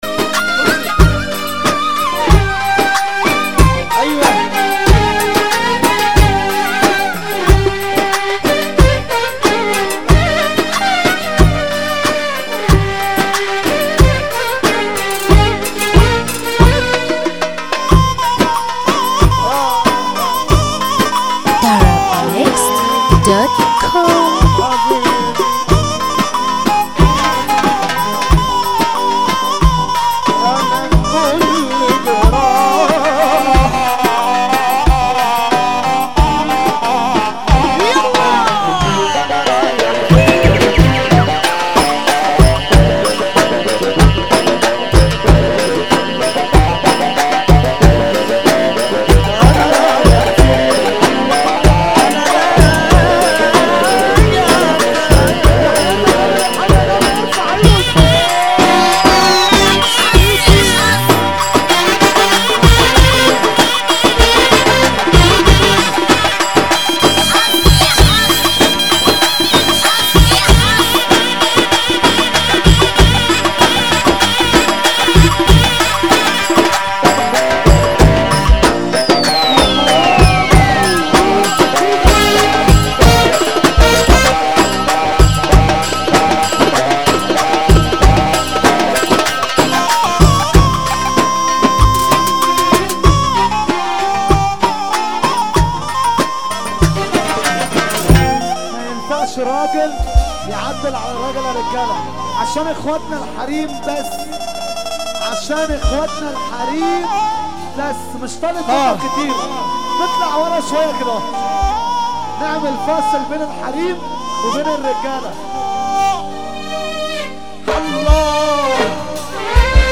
موال
بشكل حزين جدا